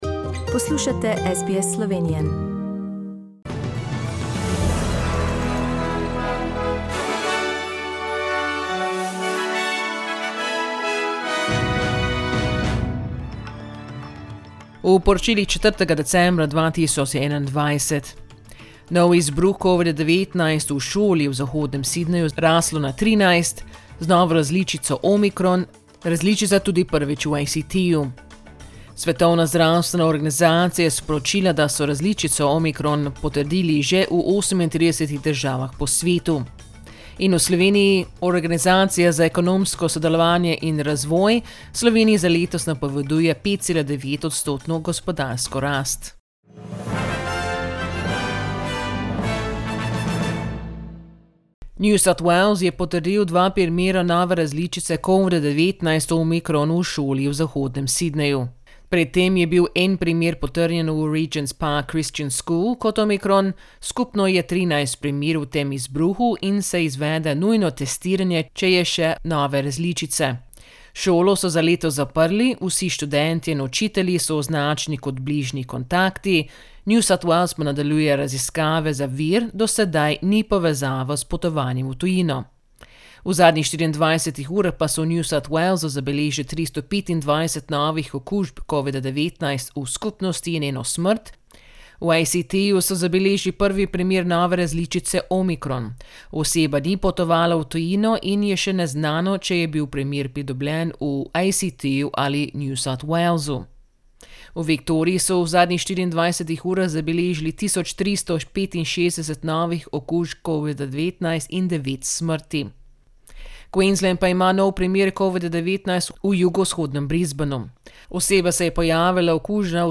Poročila Radia SBS v slovenščini 04.decembra